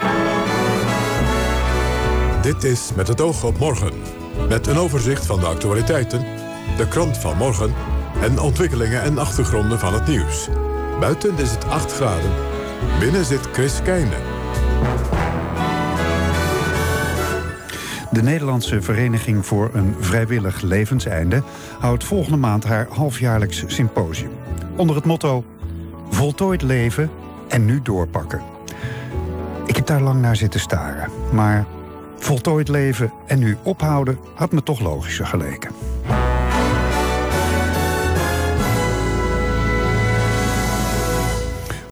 FM via antenne, 19 oktober